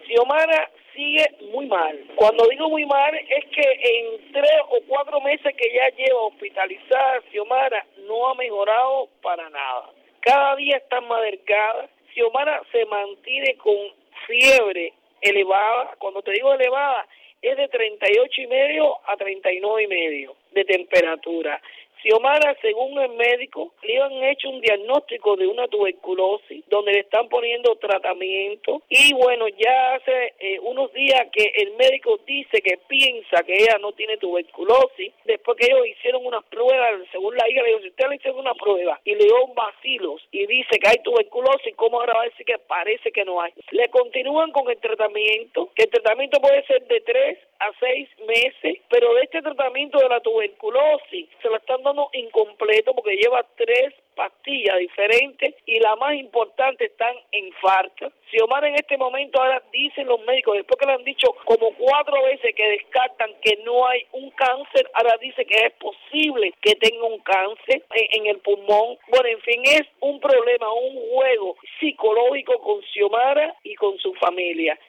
Declaraciones de Berta Soler